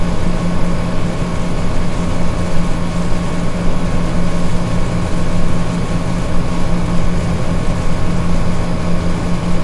风扇噪音 " 风扇
描述：窗式空调。